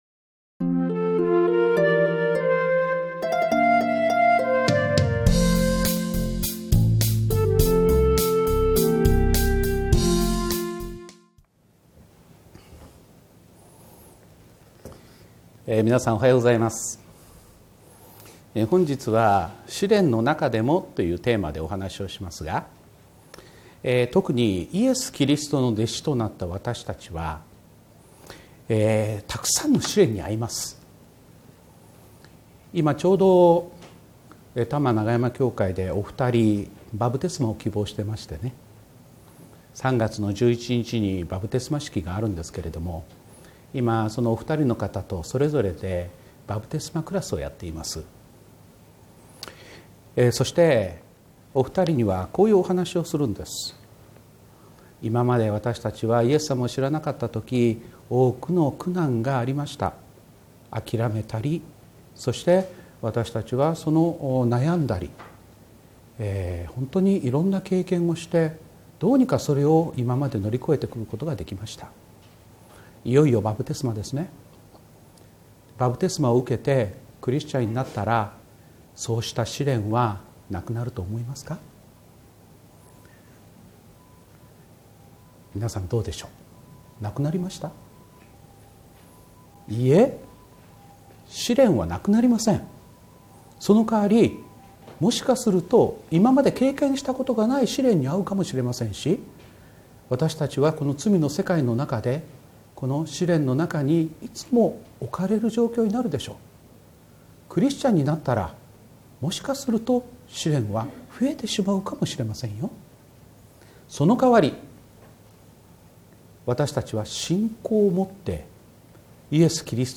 礼拝メッセージ157 試練の中でも | Hope Channel Japan
収録：セブンスデー・アドベンチスト小金井キリスト教会